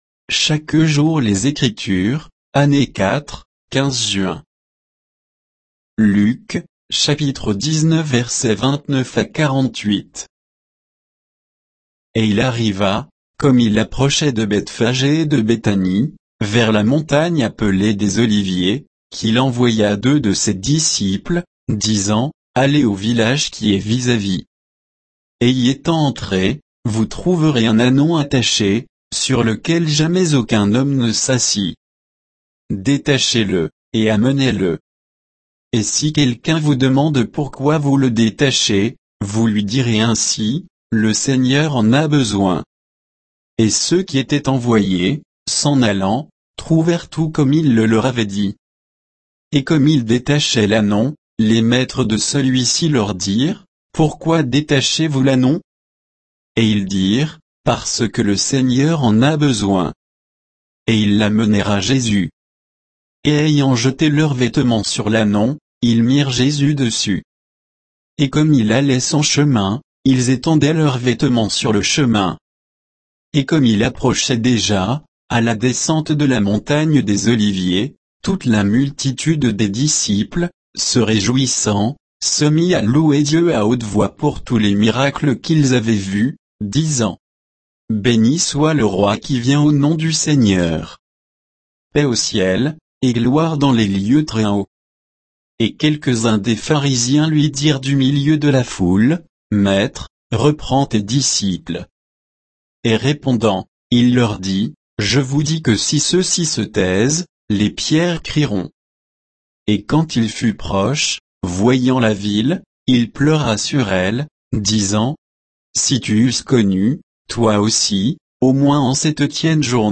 Méditation quoditienne de Chaque jour les Écritures sur Luc 19